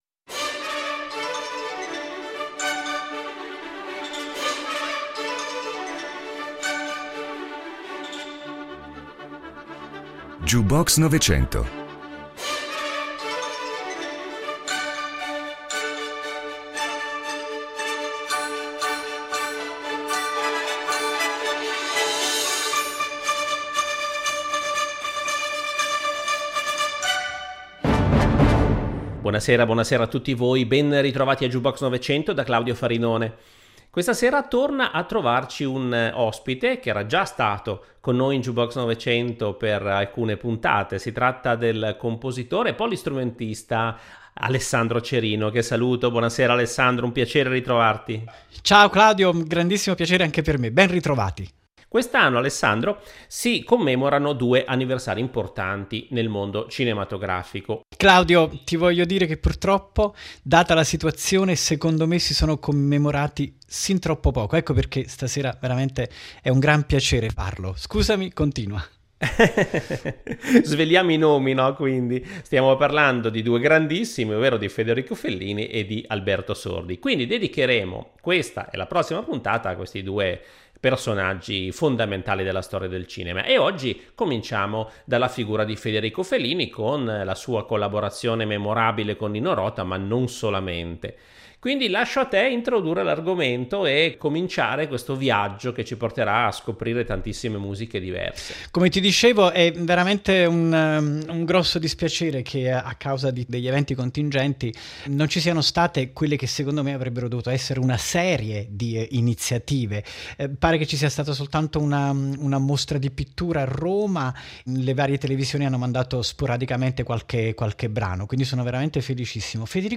polistrumentista e compositore